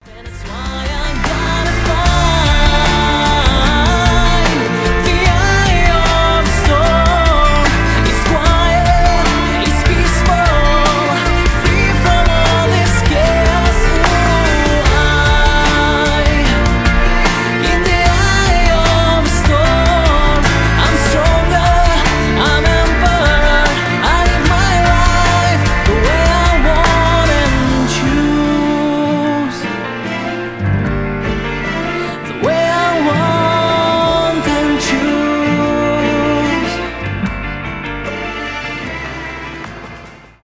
поп , красивый мужской голос